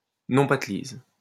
Nompatelize (French pronunciation: [nɔ̃patliz]